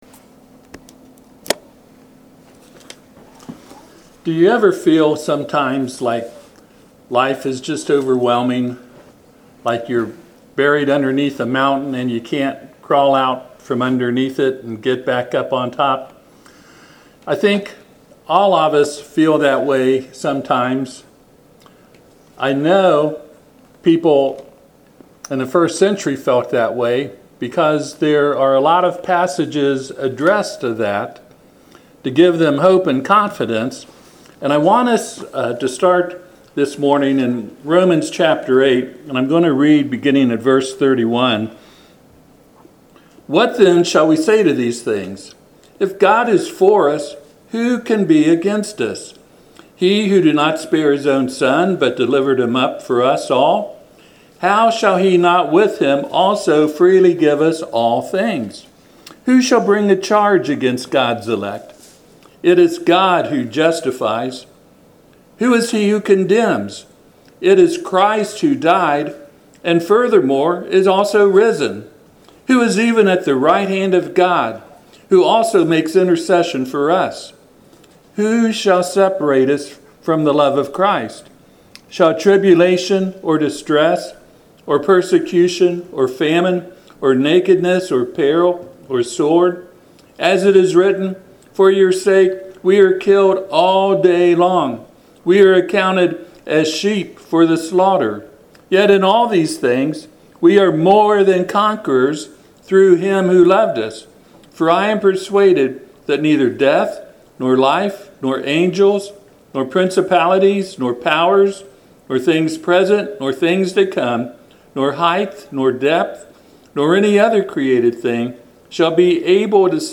Passage: Romans 8:35-39 Service Type: Sunday AM https